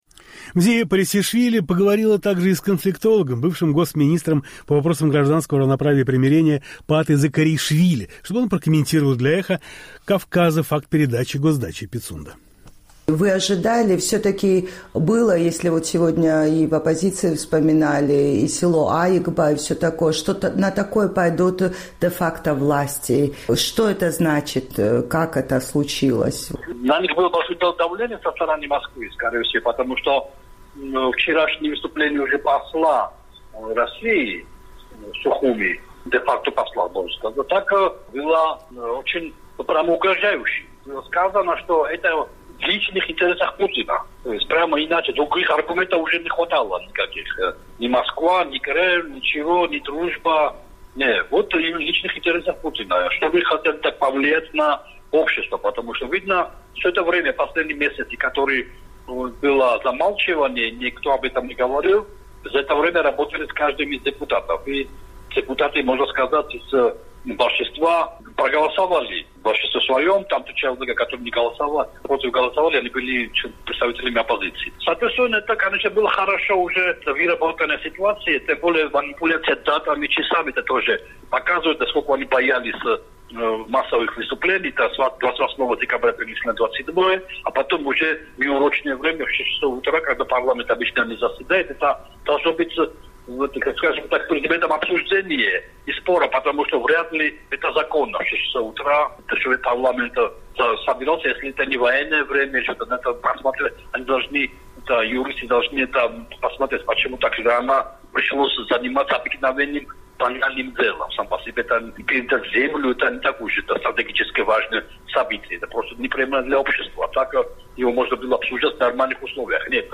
Конфликтолог, бывший госминистр по вопросам гражданского равноправия и примирения Паата Закареишвили комментирует для «Эха Кавказа» факт передачи РФ госдачи «Пицунда».